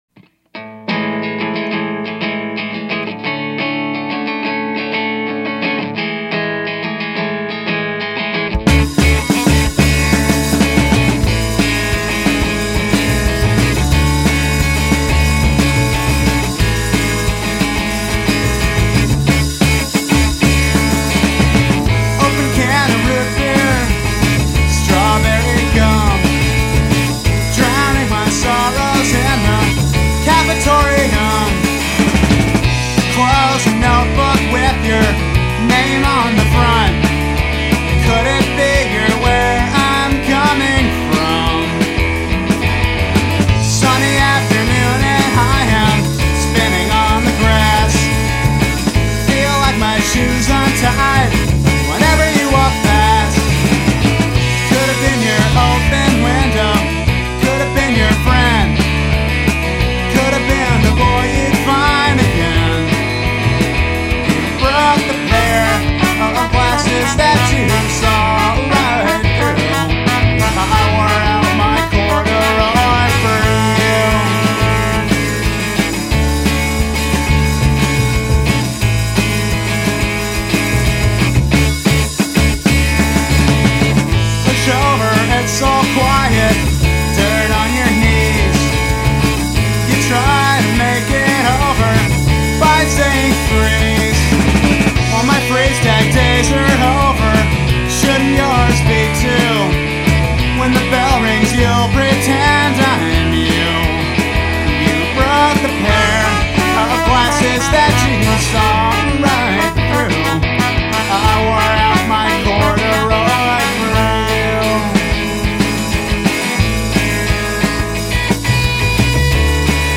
Your Minneapolis-based musician and songwriter